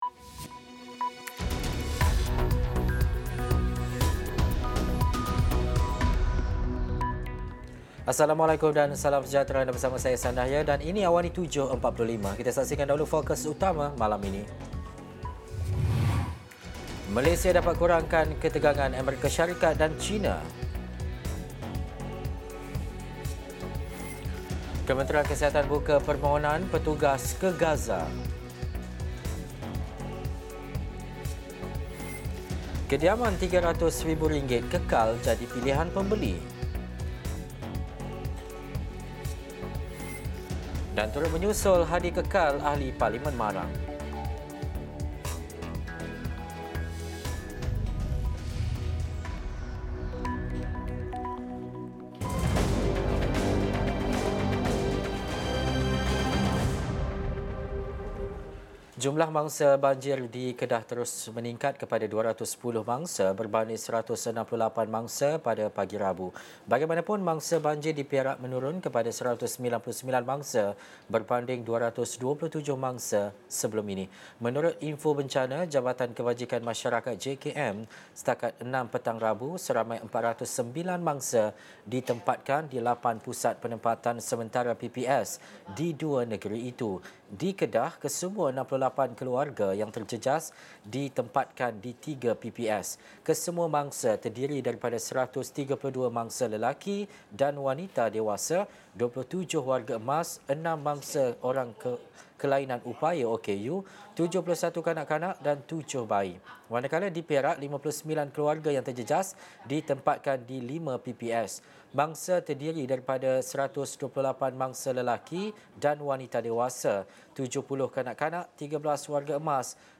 Laporan eksklusif dan terkini